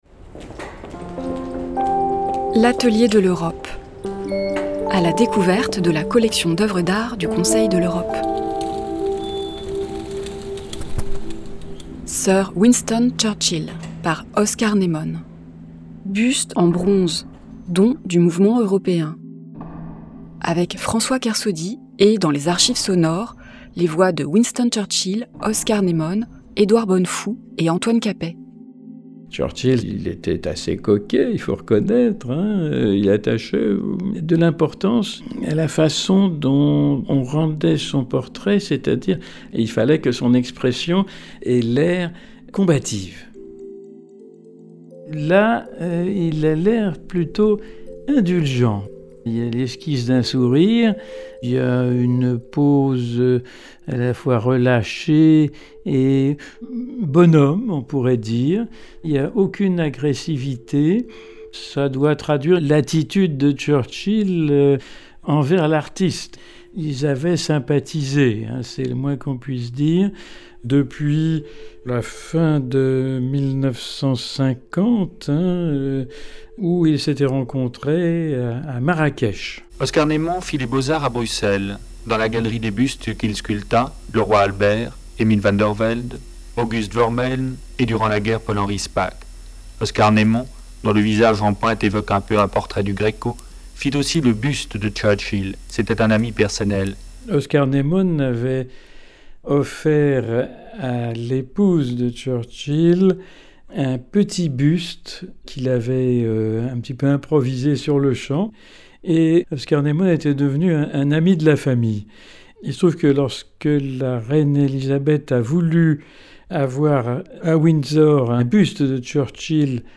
historien, spécialiste de Winston Churchill